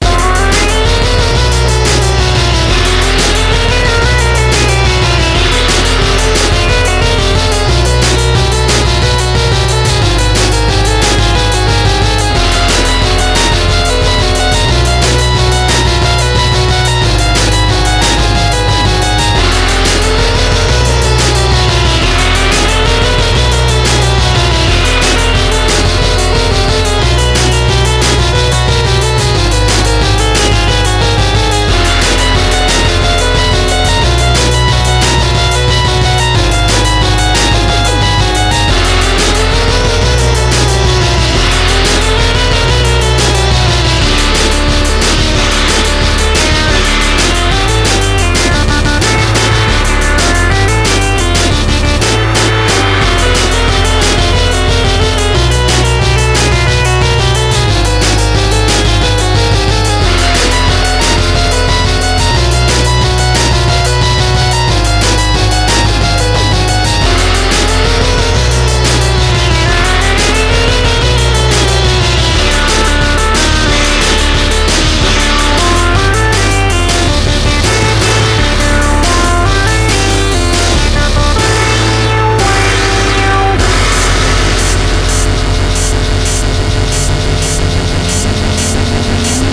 NEW WAVE / ELE POP / SYNTH POP
エレクトリック・ホワイト・ファンク/エレポップな名曲満載！